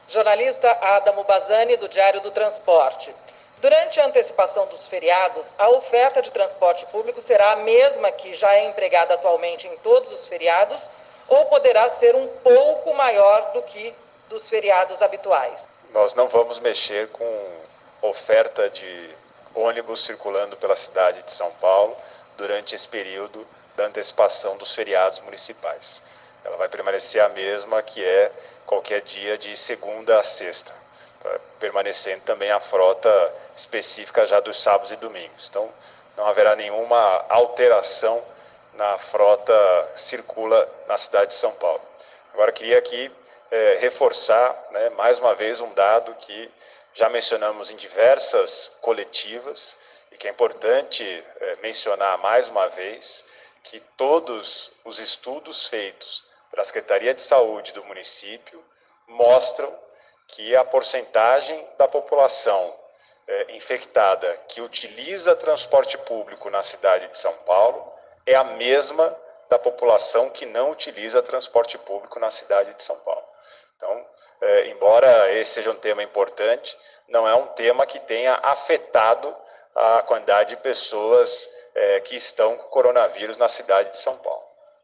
O prefeito Bruno Covas, em resposta ao Diário do Transporte, disse que durante o período de feriado antecipado a frota de ônibus da semana não terá alteração, permanecendo os horários praticados atualmente em dias úteis.